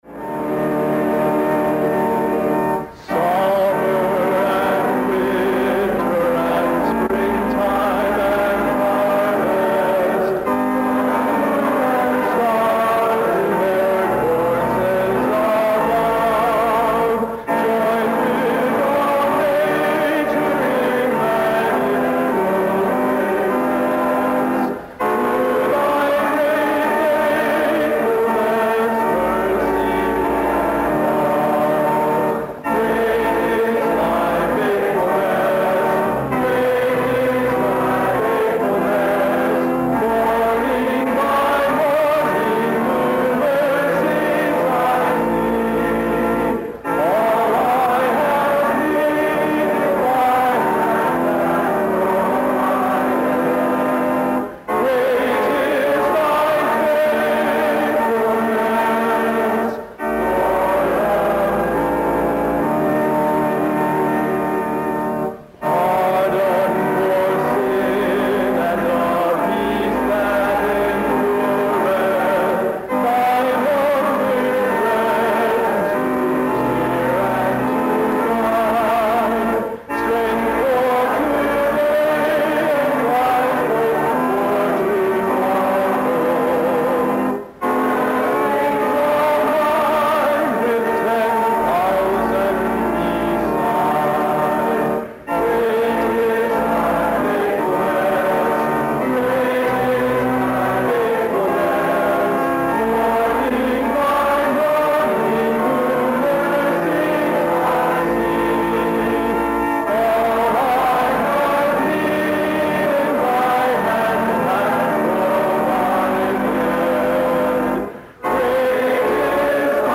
at the 3rd Annual Staley Distinguished Christian Scholar Lectureship Program at Cedarville College